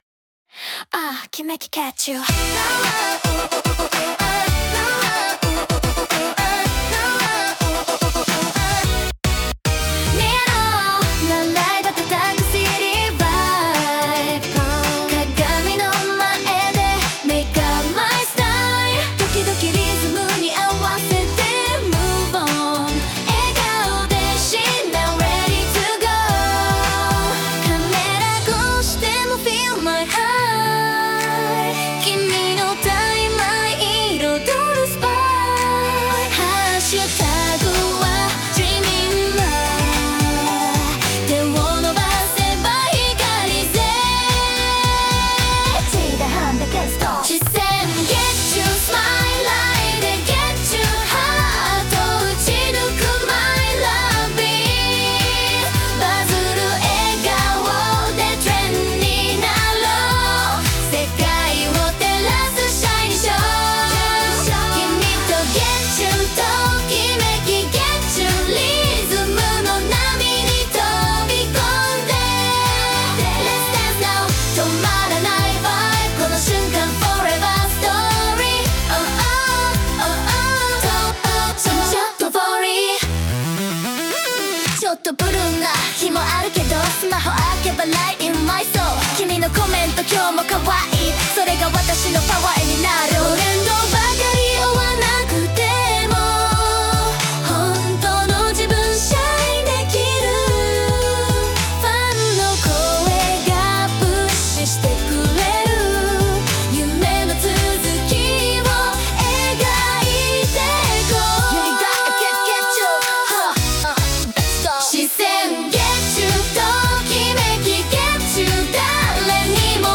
ステージのきらめきを感じるアイドルチューン